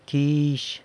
quizh[quiizh]